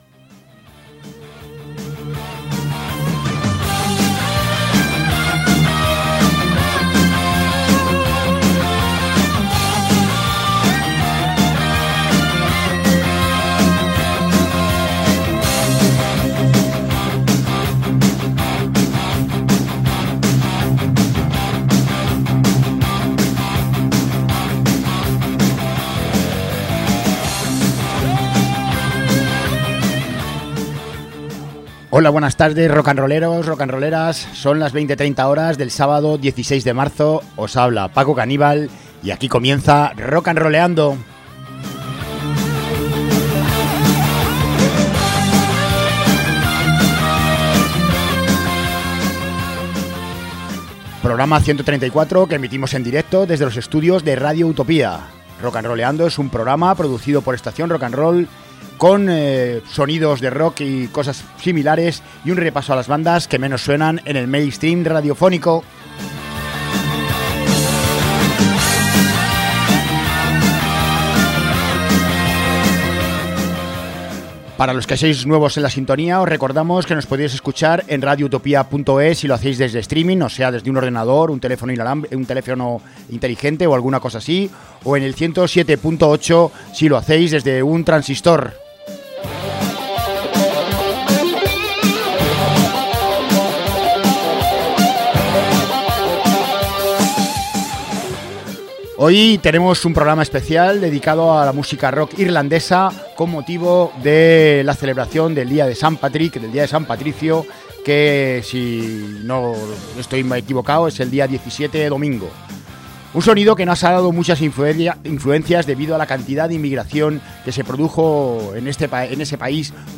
Hoy tenemos programa especial dedicado al música rock irlandesa con motivo de la celebración del día de Saint Patrick, un sonido que nos ha dado muchas influencias debido a la cantidad de inmigración que se produjo de este país durante la gran hambruna que lo asoló.
Con un sonido alternativo muy cercano al pop-rock es una formación con un muy buen futuro por delante.